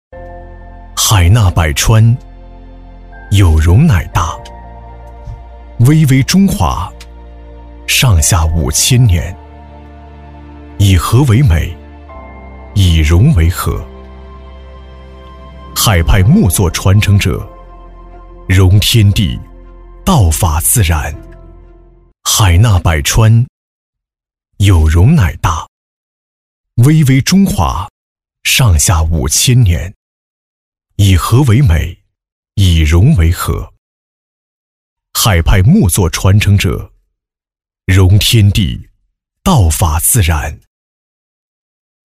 男189-【大气磁性】国家地理
男189-【大气磁性】国家地理.mp3